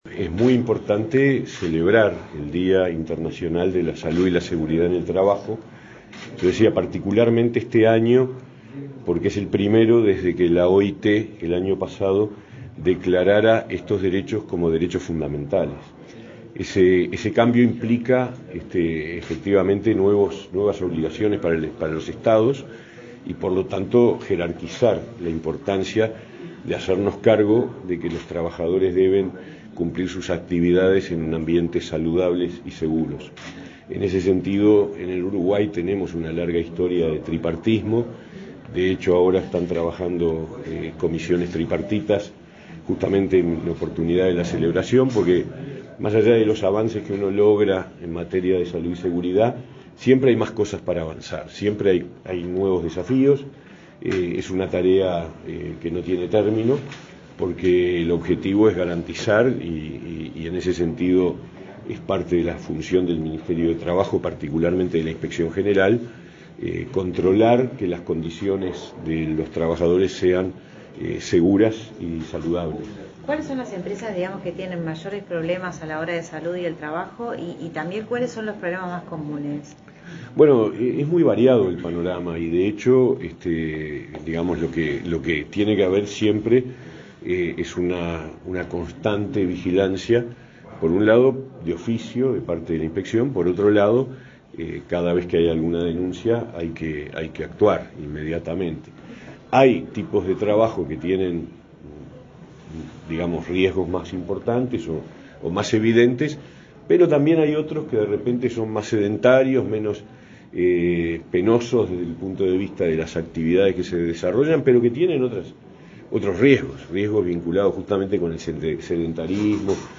Declaraciones a la prensa del ministro de Trabajo, Pablo Mieres
Declaraciones a la prensa del ministro de Trabajo, Pablo Mieres 28/04/2023 Compartir Facebook X Copiar enlace WhatsApp LinkedIn El titular del Ministerio de Trabajo, Pablo Mieres, participó en el acto realizado en esa cartera por el Día Mundial de la Seguridad y la Salud en el Trabajo. Luego dialogó con la prensa.